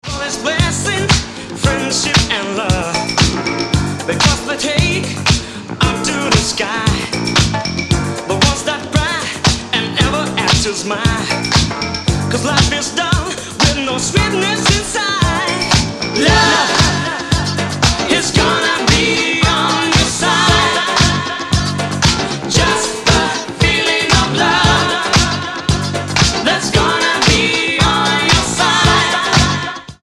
Two rare Italo-disco tracks from 1981 reissued.